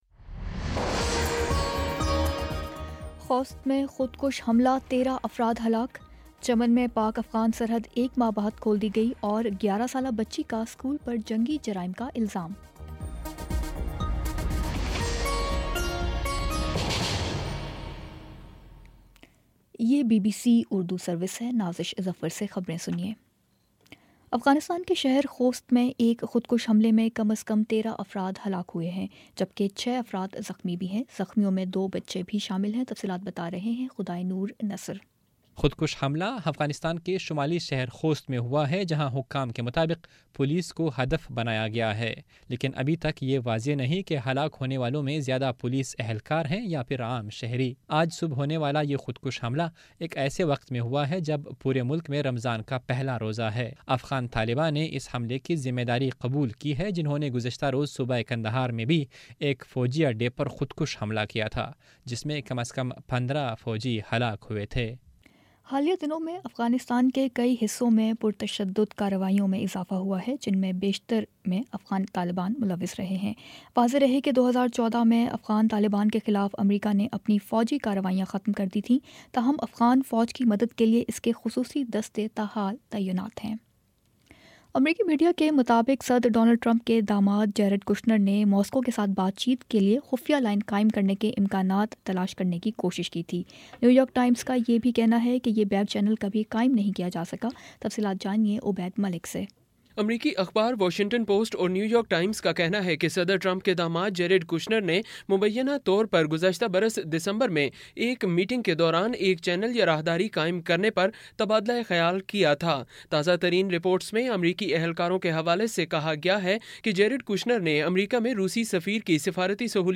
مئی 27 : شام پانچ بجے کا نیوز بُلیٹن